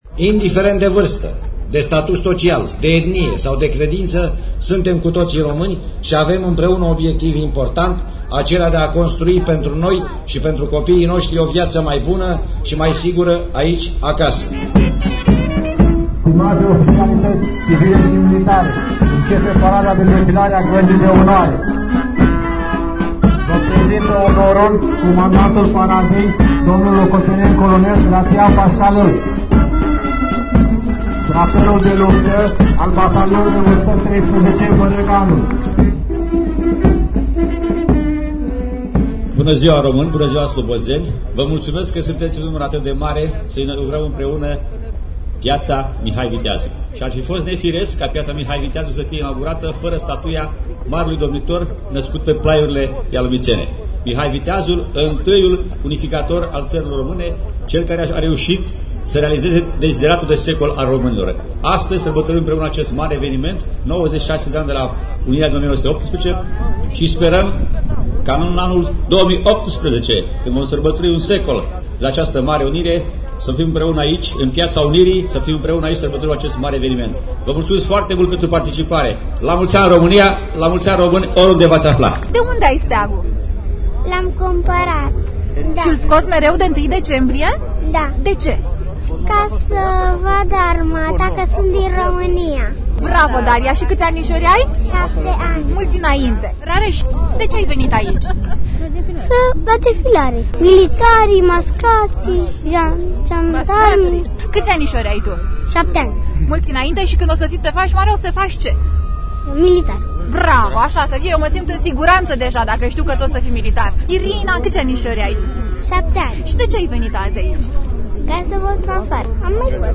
Contribuţia ialomiţenilor la actul istoric de la 1 decembrie, alocuţiunile autorităţilor şi impresiile celor mai mici spectatori de la parada militară, într-un reportaj radio CAMPUS:
reportaj-UNIRE.mp3